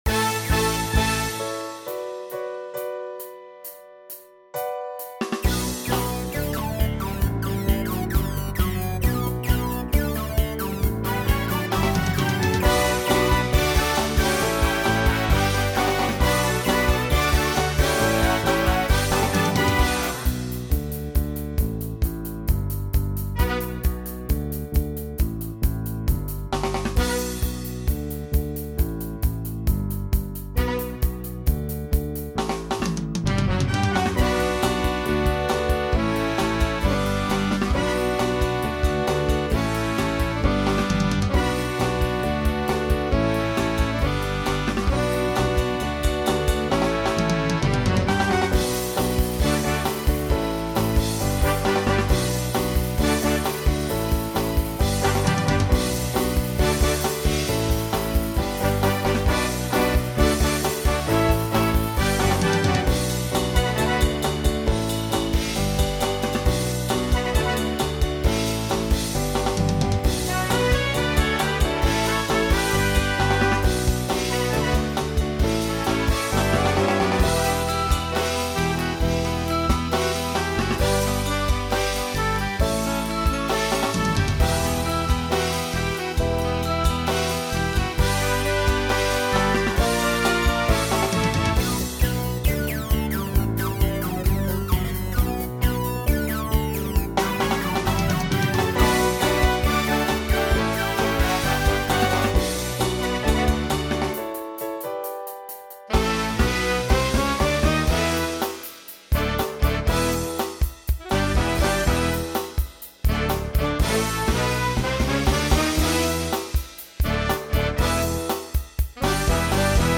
SATB
SSA Instrumental combo Genre Pop/Dance